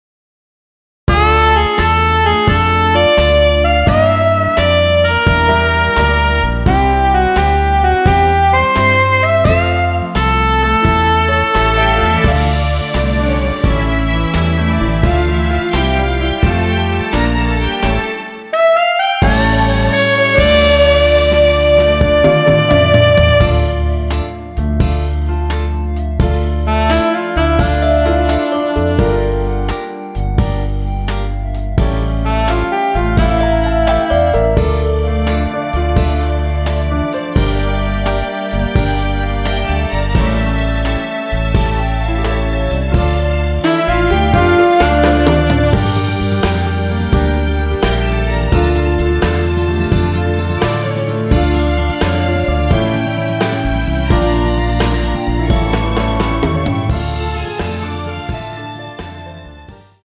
원키에서(-1)내린 멜로디 포함된 MR입니다.(미리듣기 확인)
Dm
◈ 곡명 옆 (-1)은 반음 내림, (+1)은 반음 올림 입니다.
멜로디 MR이라고 합니다.
앞부분30초, 뒷부분30초씩 편집해서 올려 드리고 있습니다.